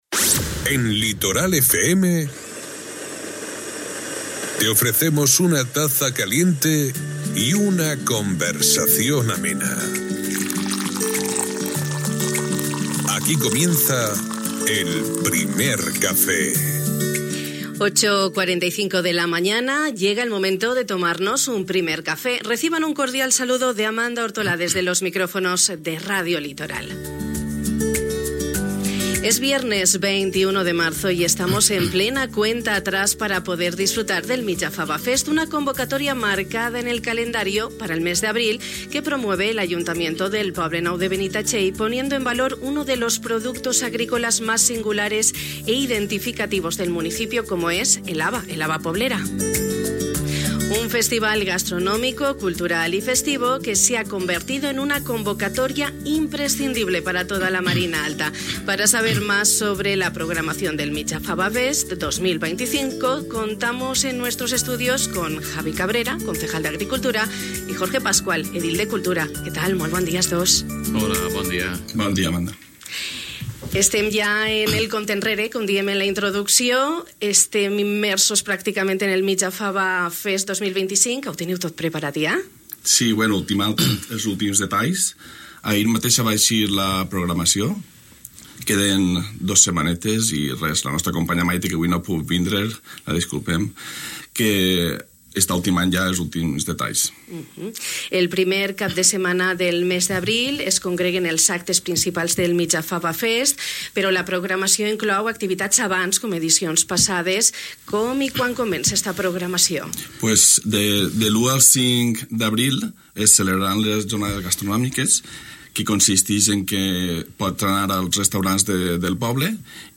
Un festival gastronómico, cultural y festivo sobre el que hemos conversado con Javi Cabrera, concejal de Agricultura y Jorge Pascual, edil de Cultura.